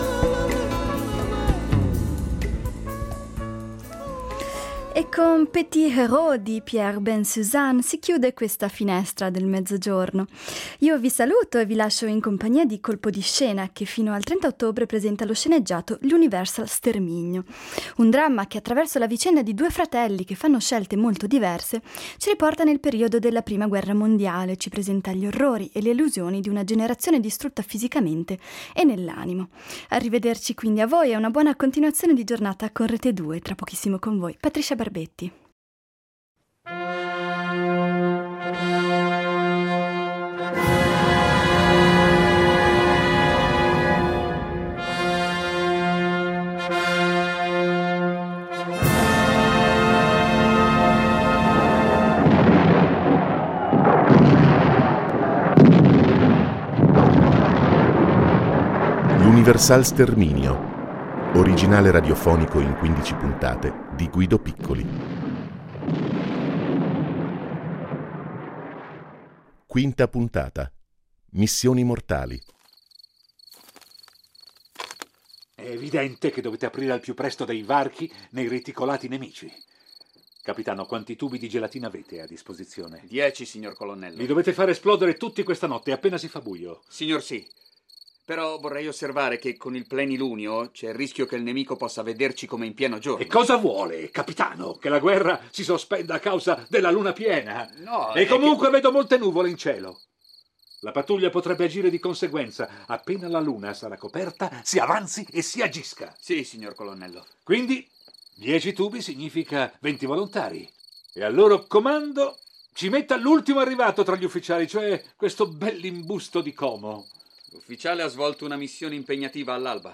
Ed è proprio in Svizzera dove si rifugia Angelo, un frontaliere comasco, in contrasto con il fratello Giulio, che risponde alla chiamata al fronte per essere spedito, col grado di sottotenente, sul fronte dolomitico. Le vicissitudini parallele dei due giovani compongono lo sceneggiato in 15 puntate intitolato “L’Universal sterminio”, che illustra gli orrori e le illusioni di una generazione, conosciuta in Italia con la definizione “i ragazzi del ‘99”, distrutta anche nell’animo da una barbarie insieme organizzata e dissennata che gli eventi di questi giorni nell’Est europeo evocano in maniera inquietante.